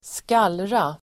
Uttal: [²sk'al:ra]